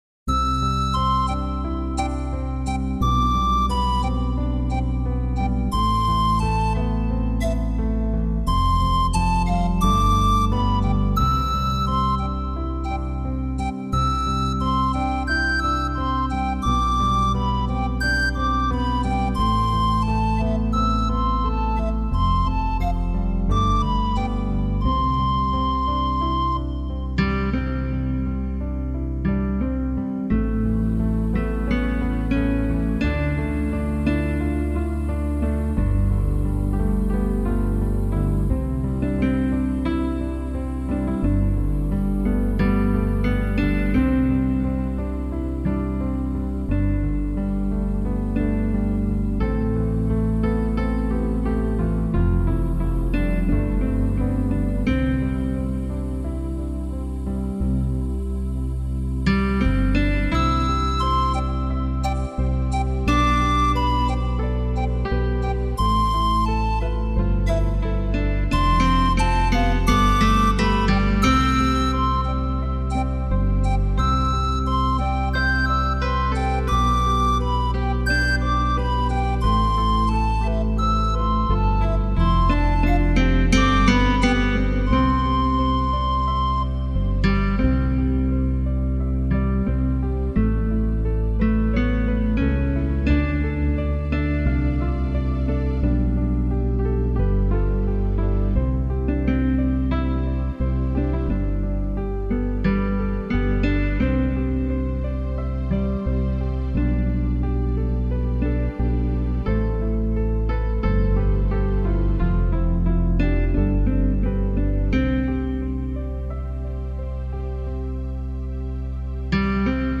九首充满大自然气息的纯美音乐
配合木吉他，竖琴等乐器